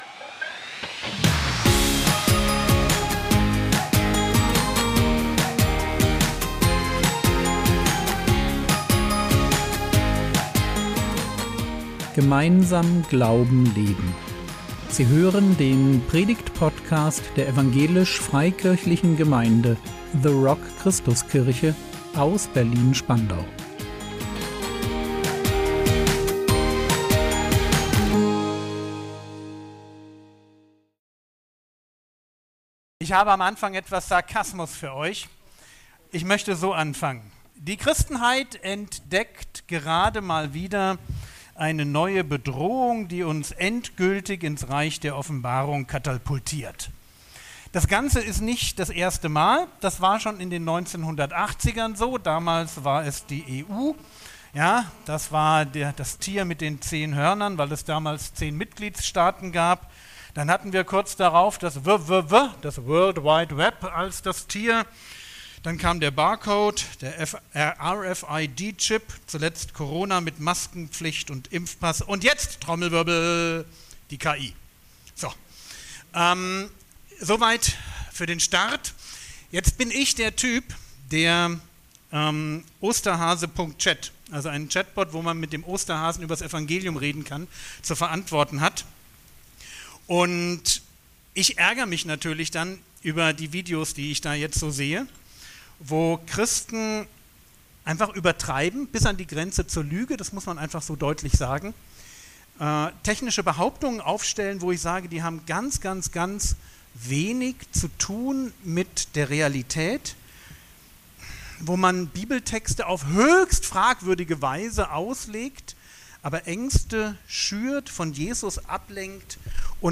KI und Evangelisation | 28.09.2025 ~ Predigt Podcast der EFG The Rock Christuskirche Berlin Podcast